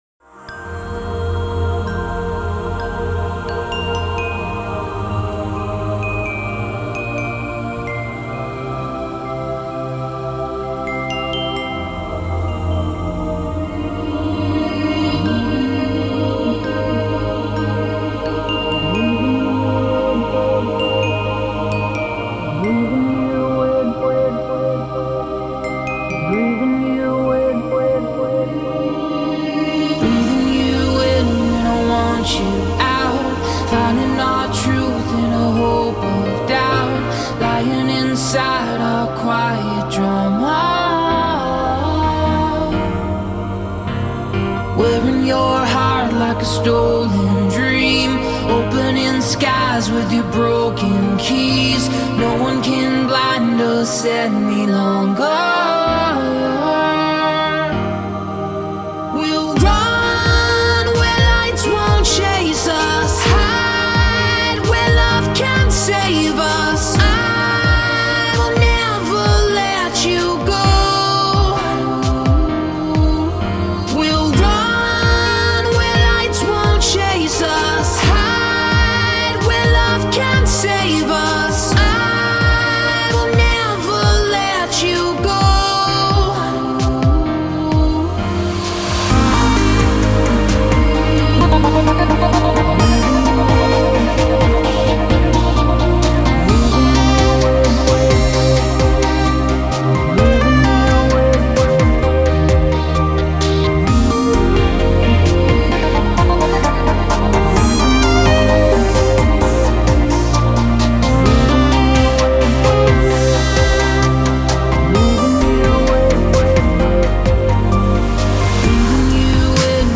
Chillout Version